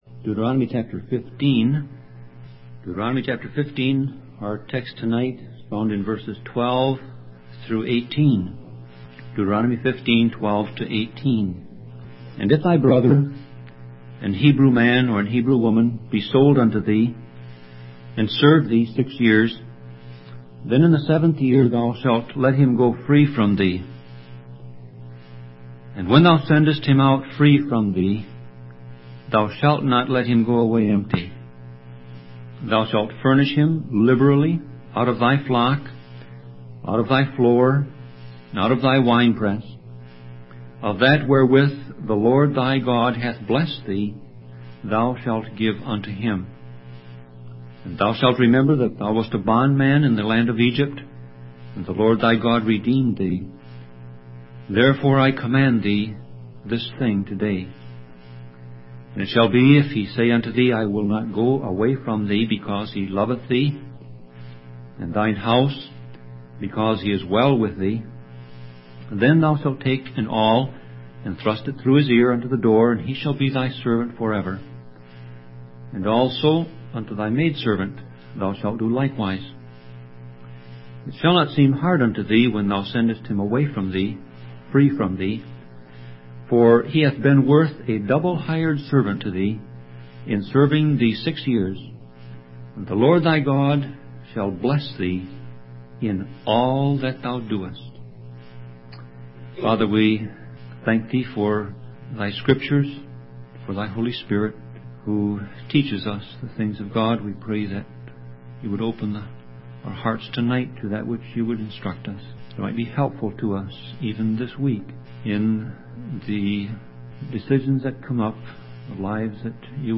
Sermon Audio Passage: Deuteronomy 15:12-18 Service Type